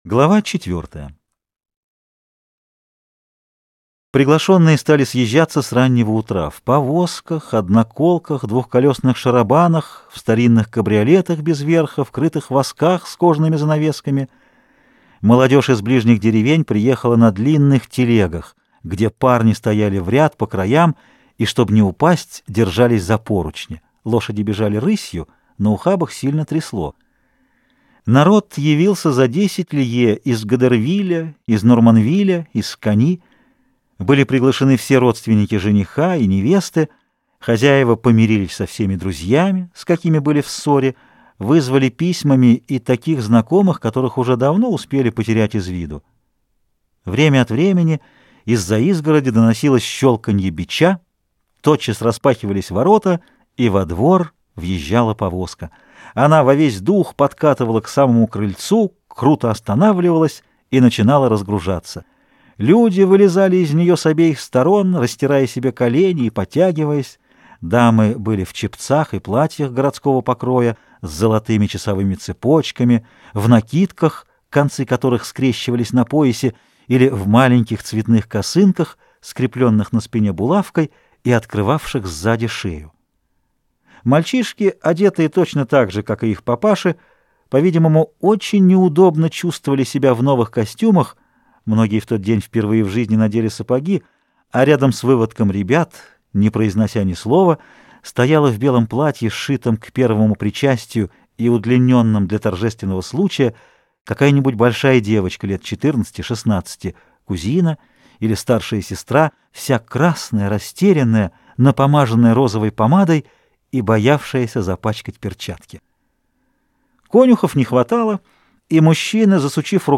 Аудиокнига Госпожа Бовари | Библиотека аудиокниг